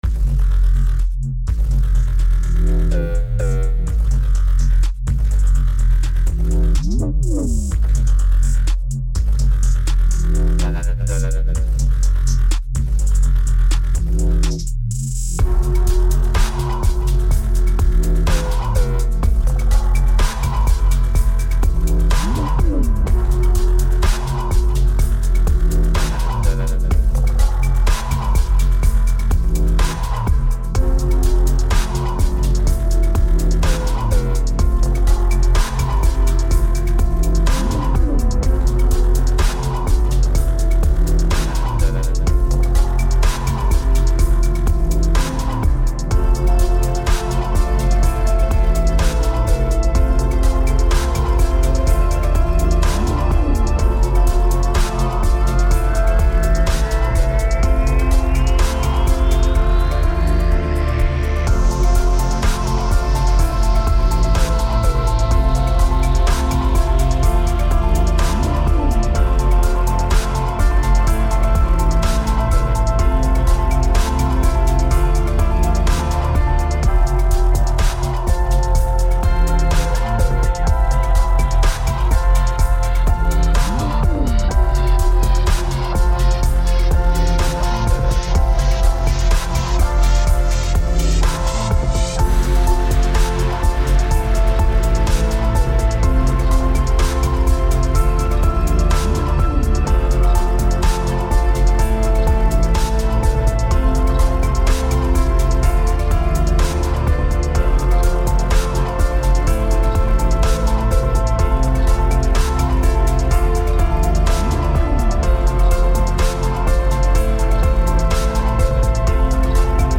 Tempo 125BPM (Allegro)
Genre Slow Dubstep/Trap/Cinematic cross
Type Instrumental
Mood Conflicting (Energetic/Aggressive/Chilled)